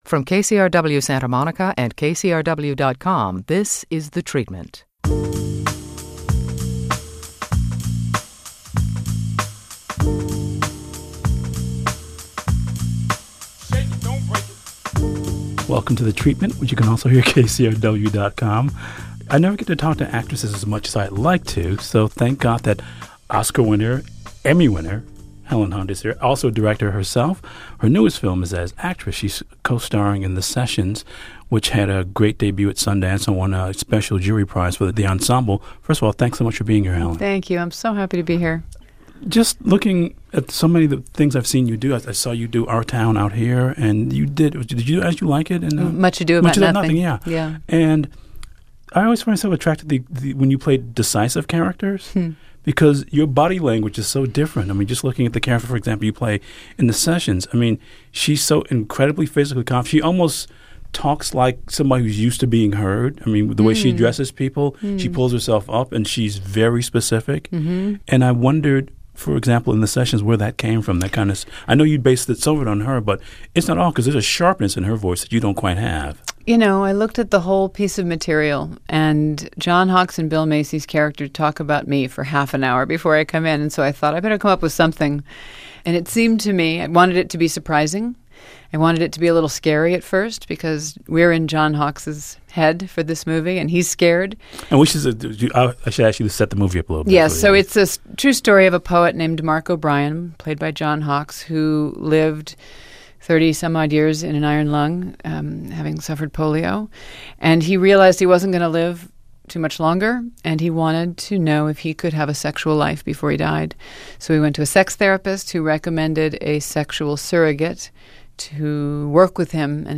Elvis talks to Oscar winning actress Helen Hunt about her most recent role as real life sex surrogate Cheryl Cohen Greene in "The Sessions."